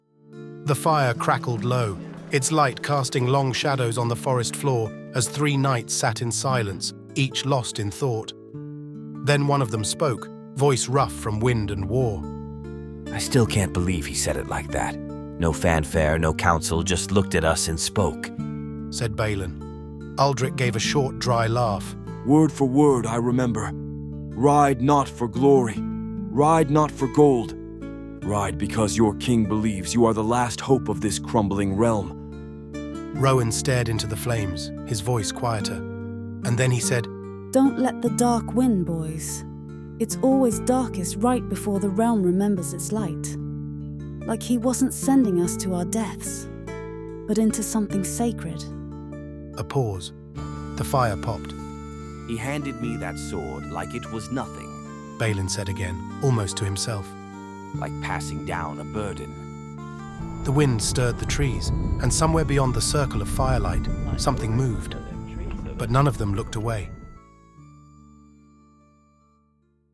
A narrator voice is generated for scene setting and any non-dialogue text.
These can be simple things like footsteps, wind, or a door opening.
The mood of the music is based on the tone of the script.
It assigns timing, blends voices, sound effects, and music into a single audio track.
The end result is an audio version of the Codex entry, with characters speaking in their own voices, supported by music and atmosphere. It's like a mini radio play, made almost entirely with AI tools.
codex-audiobook-demo.wav